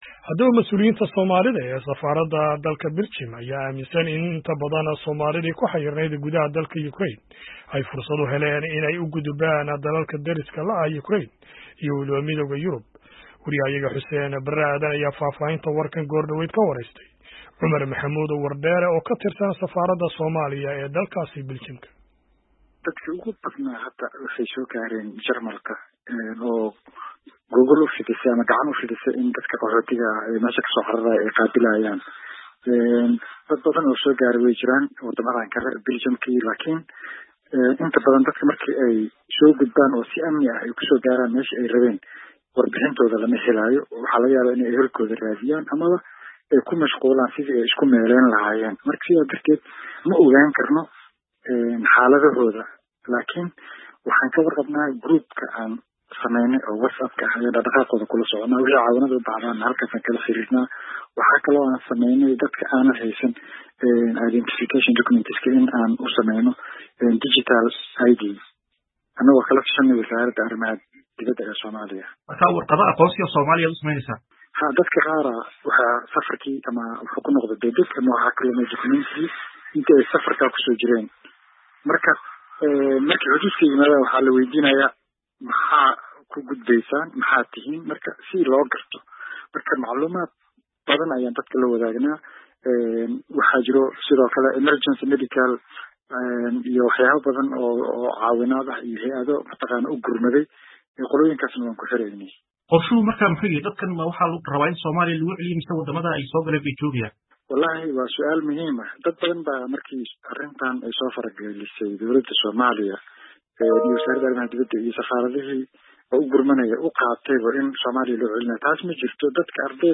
Wareysi aan la yeelanay dublumaasi Soomaali ah oo ka hadlaya xaaladda Soomaalida Ukraine